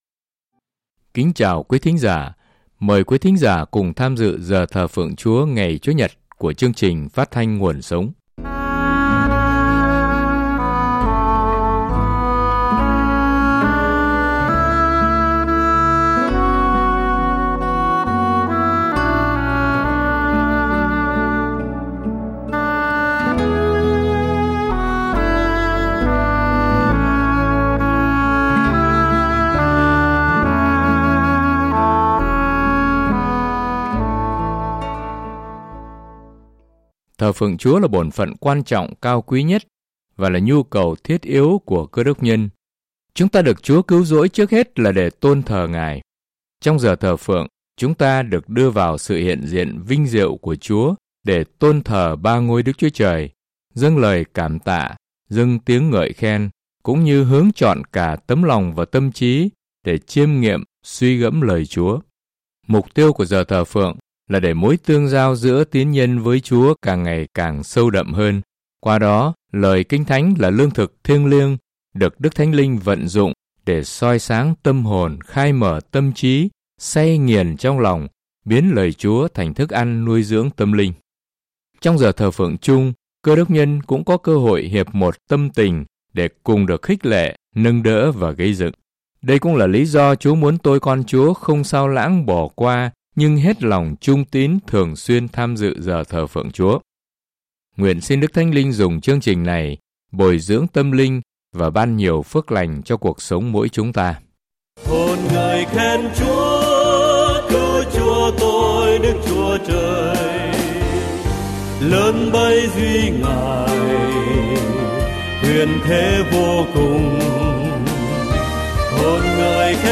Bài 186: Chiến Đấu Bằng Cầu Nguyện – Ê-phê-sô 6:18 | Thờ Phượng Giảng Luận | Đài nguồn sống